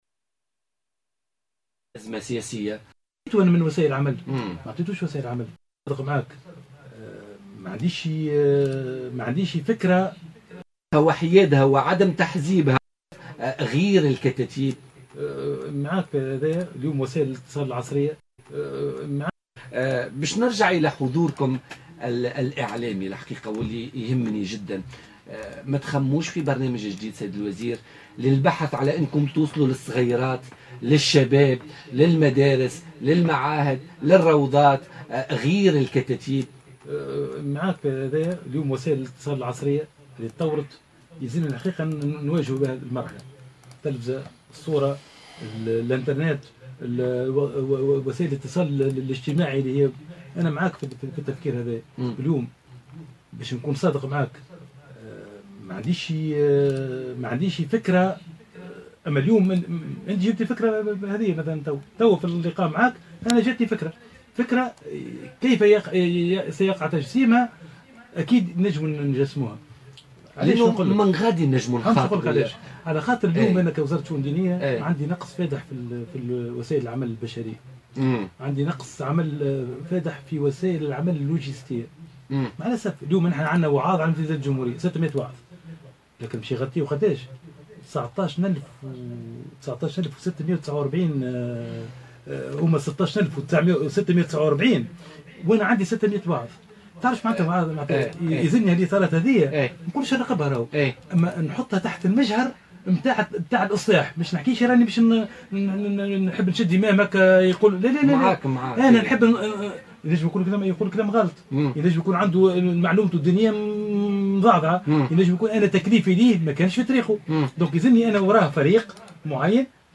أكد وزير الشؤون الدينية أحمد عظوم ضيف بولتيكا اليوم الثلاثاء 23 ماي 2017 أن الوزارة تنقصها الإمكانيات البشرية خاصة بعد إلغاء المناظرة الأخيرة التي كانت ستعزز الوزارة بالإطارات.